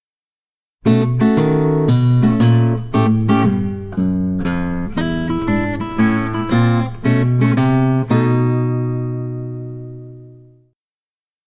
В основе примера - хроматическая басовая линия, которая сначала движется вниз, а затем вверх.